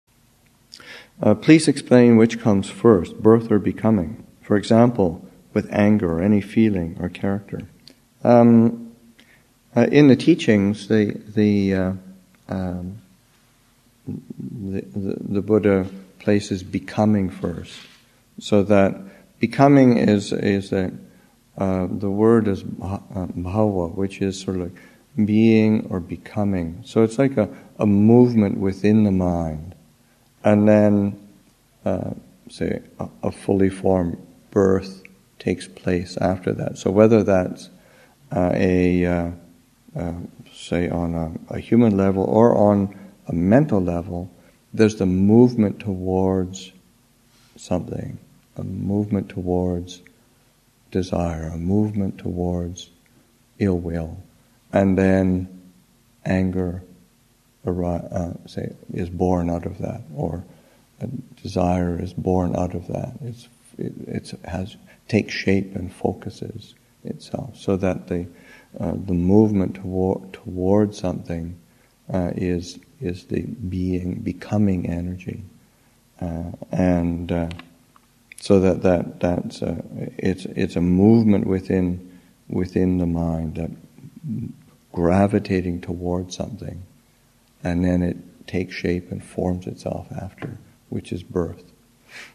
Abhayagiri Monastic Retreat 2013, Session 5, Excerpt 15